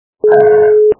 Ребенок - Отрыжка Звук Звуки Дитина - Відрижка
При прослушивании Ребенок - Отрыжка качество понижено и присутствуют гудки.